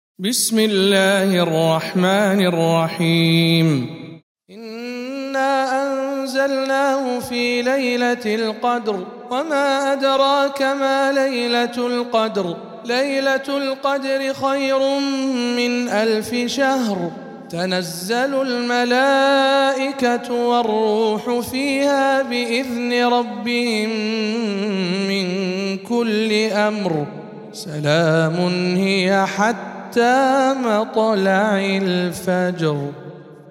سورة القدر - رواية رويس عن يعقوب